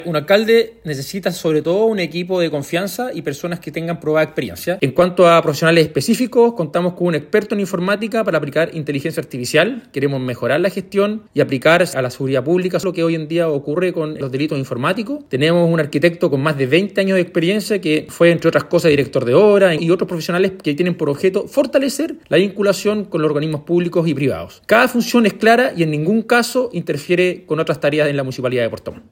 alcalde-rodrigo-wainraihgt.mp3